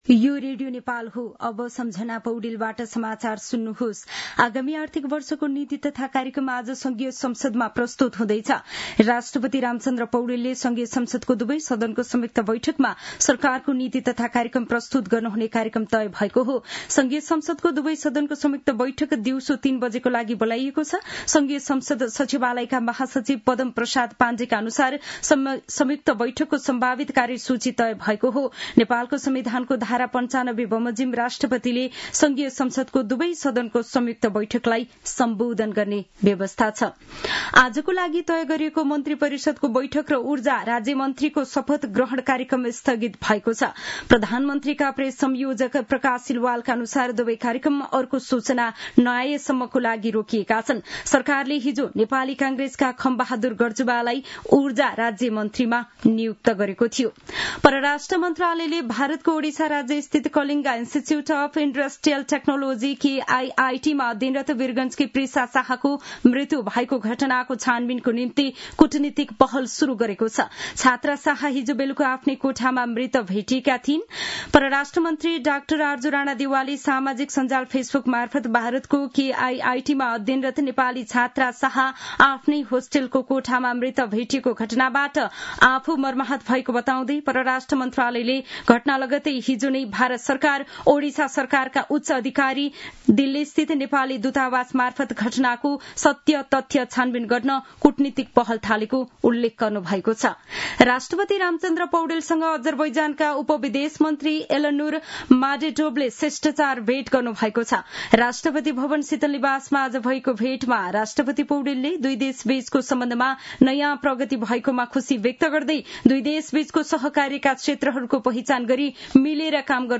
दिउँसो १ बजेको नेपाली समाचार : १९ वैशाख , २०८२
1-pm-news-.mp3